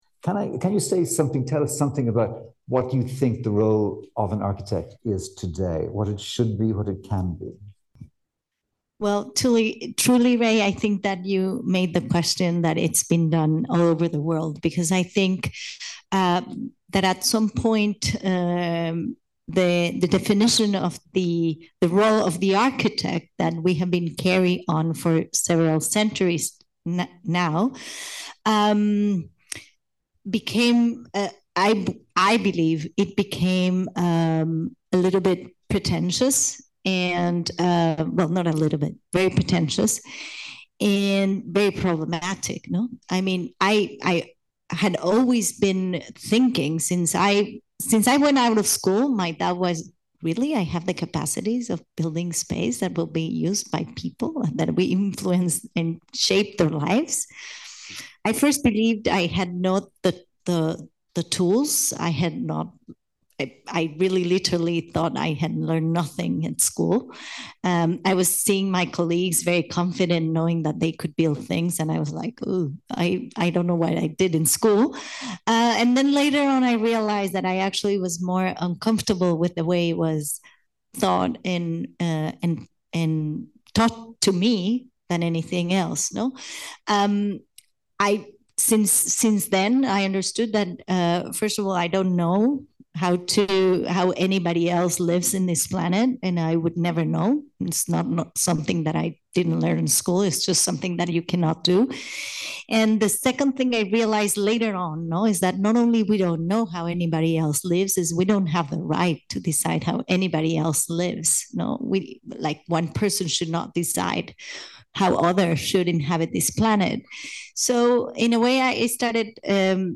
In Conversation: Tatiana Bilbao